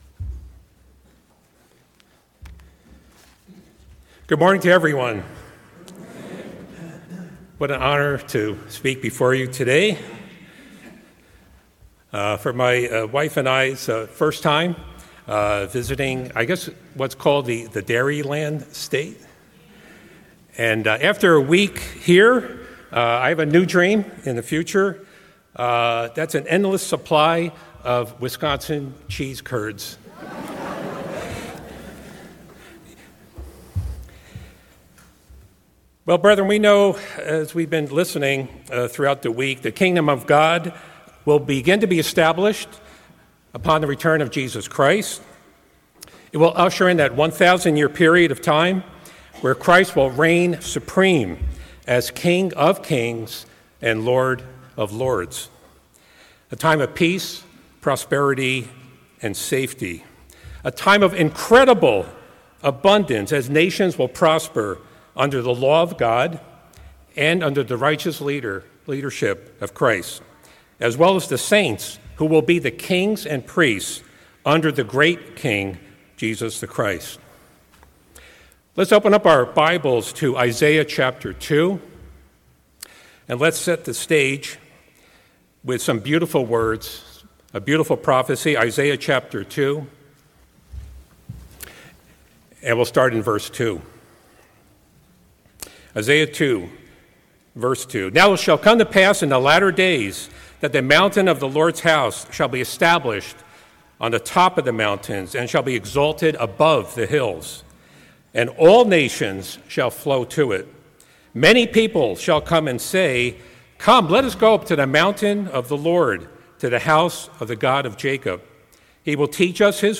Given in Lake Geneva, Wisconsin